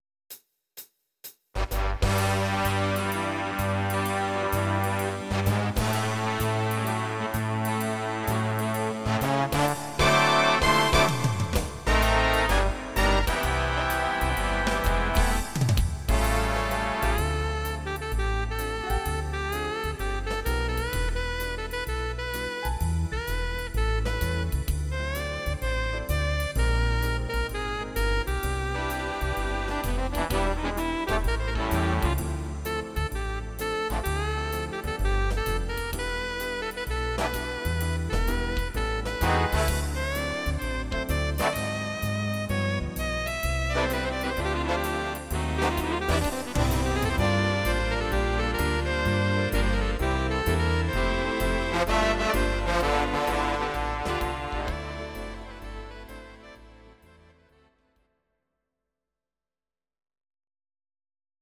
instr. Big Band